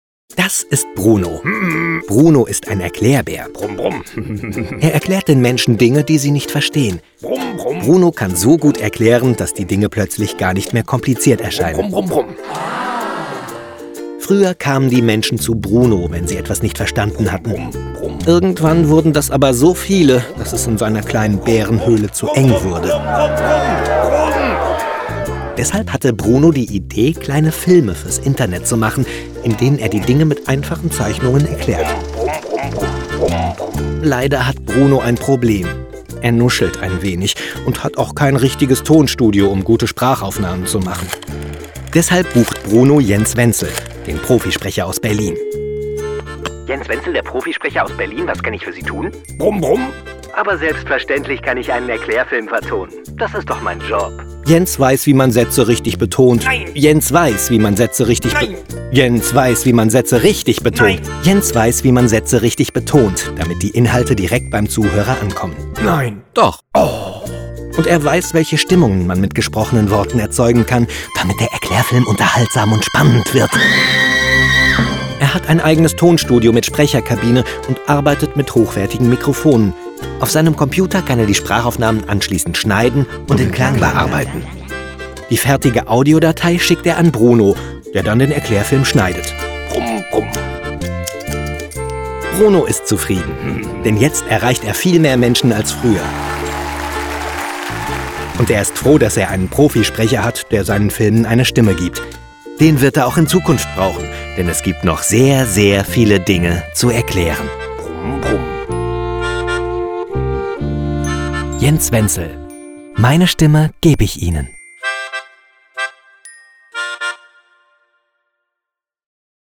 Sprecherdemo ERKLAERFILM